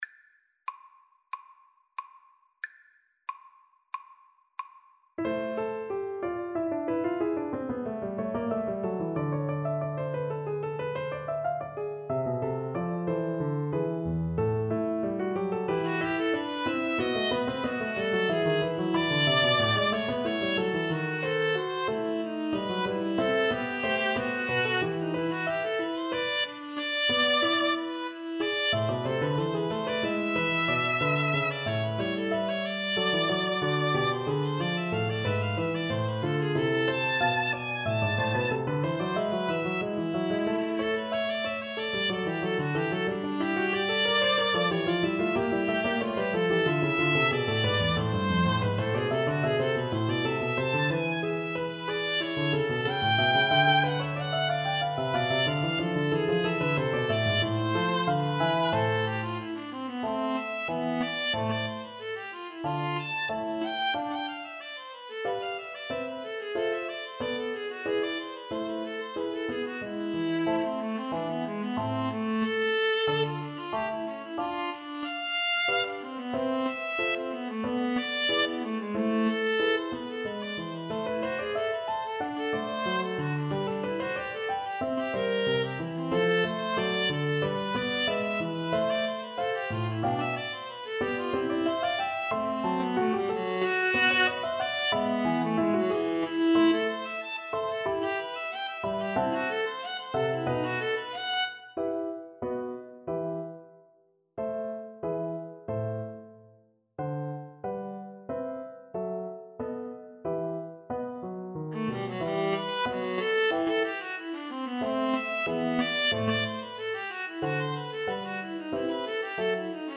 Free Sheet music for Viola-Cello Duet
A minor (Sounding Pitch) (View more A minor Music for Viola-Cello Duet )
4/4 (View more 4/4 Music)
= 92 I: Vivace (View more music marked Vivace)
Classical (View more Classical Viola-Cello Duet Music)